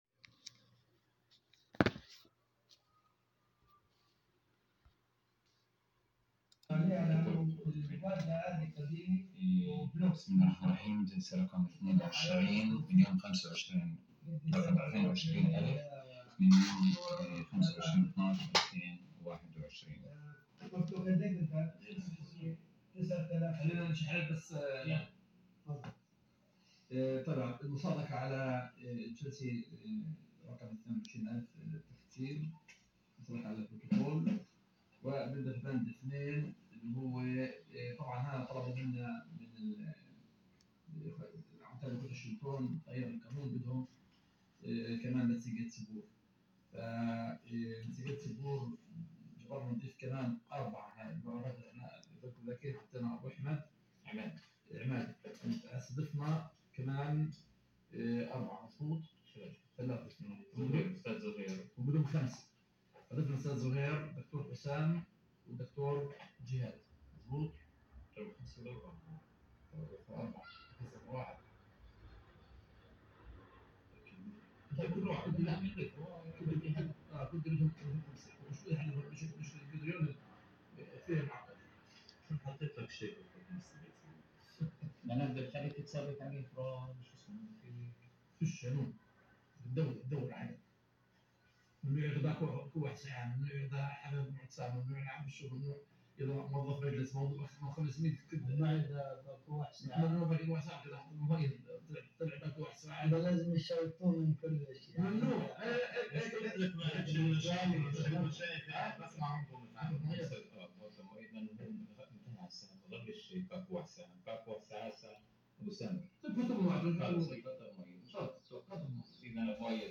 تسجيل جلسة مجلس